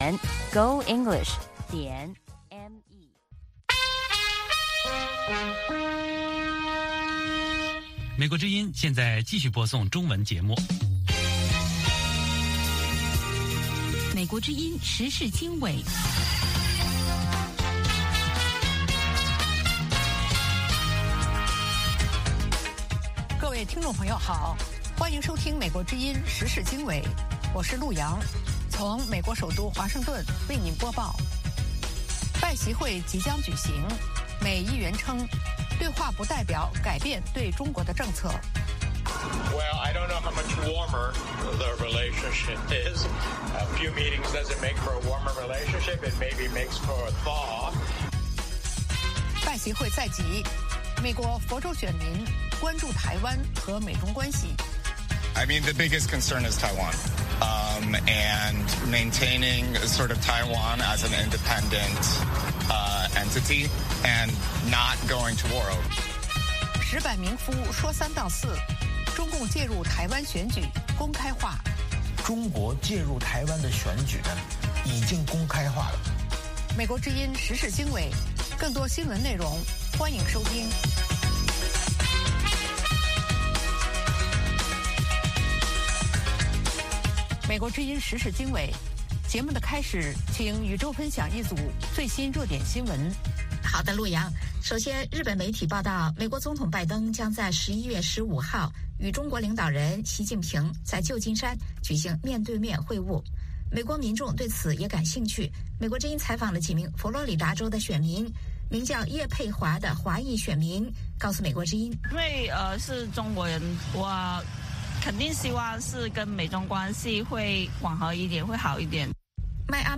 美国之音英语教学节目。